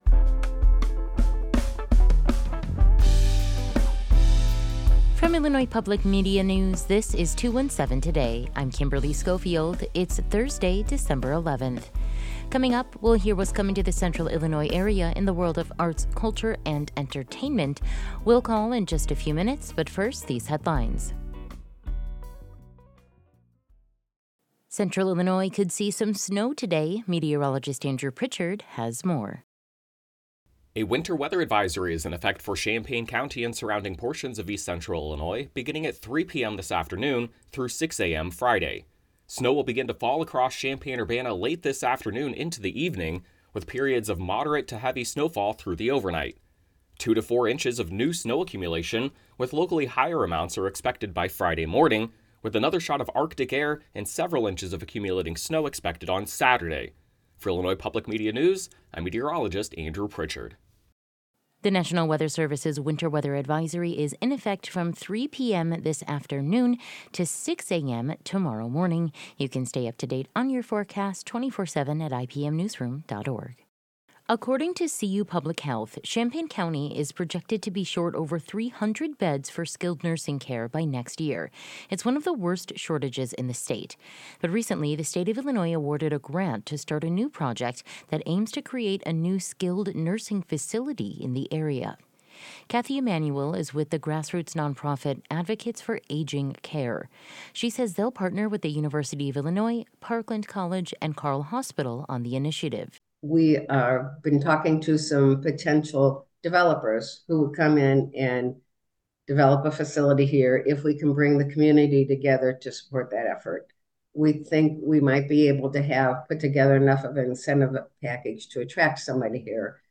Today’s headlines